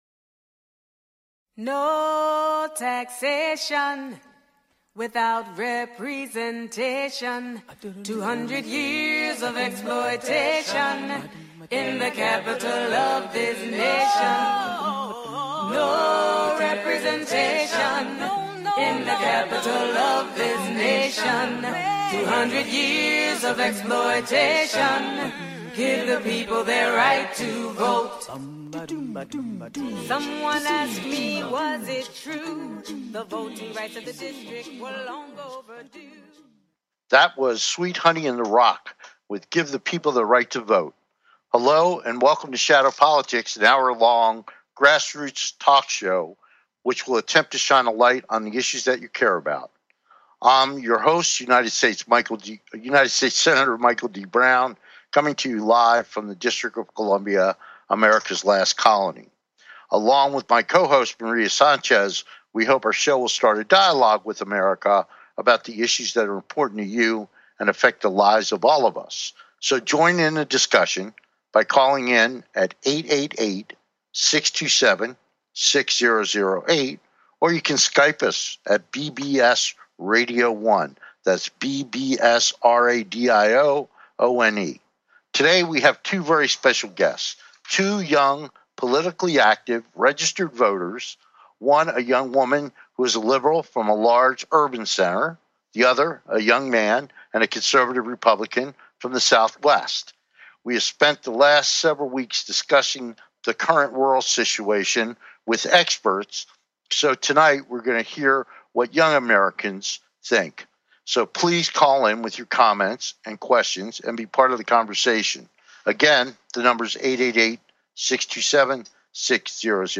Talk Show Episode, Audio Podcast, Shadow Politics and Want to know what two college students think of our world today? on , show guests , about Liberal Democrat,DePaul University,Conservative Republican,University of Arizona,voting rights,Youth Politics, categorized as Kids & Family,Philosophy,Politics & Government,Variety